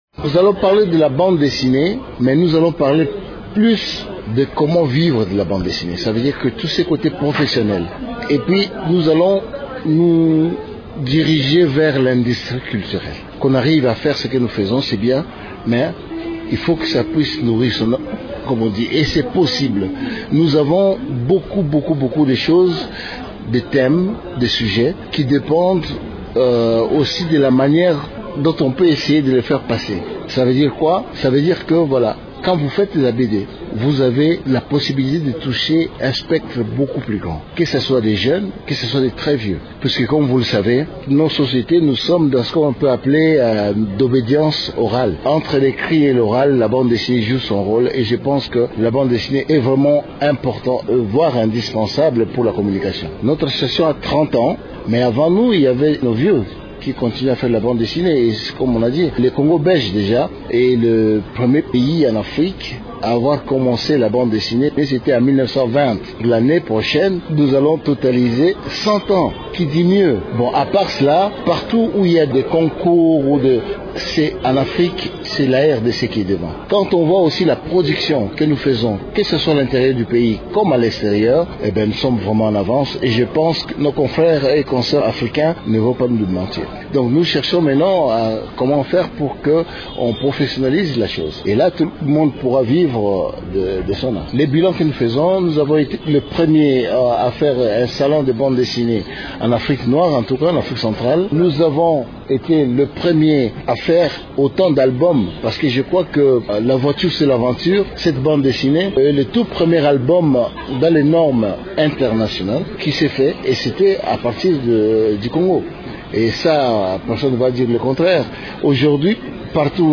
Le dessinateur Barly Baruti, président du comité organisateur, initiateur et promoteur de ce salon évoque quelques particularités de cette 6ème édition